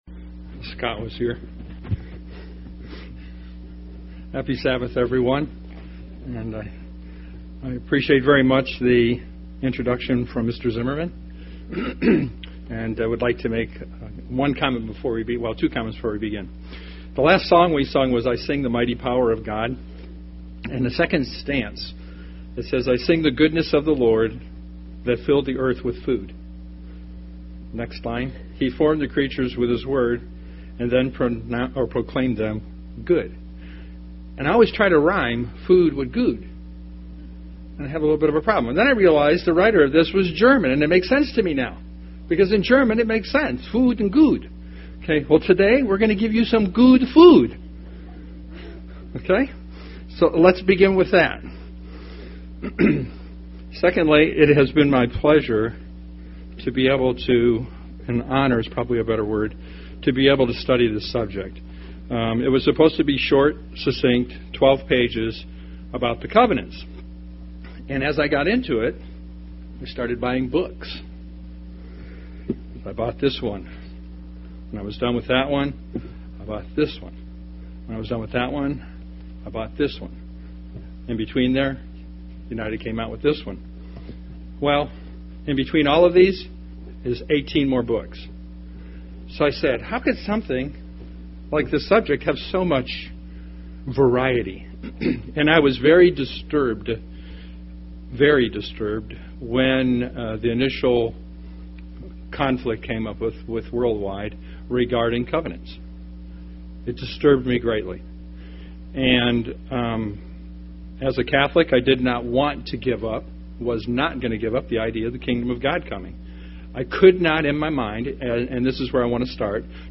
Given in Tampa, FL
UCG Sermon Studying the bible?